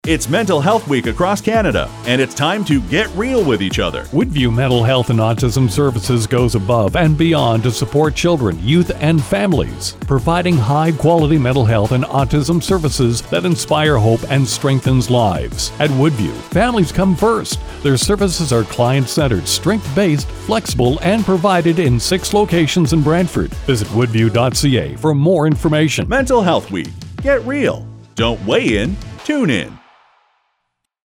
to our radio commercial!
Aired on May 2, 2022.